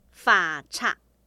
臺灣客語拼音學習網-進階學習課程-饒平腔-第五課